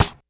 Snare_09.wav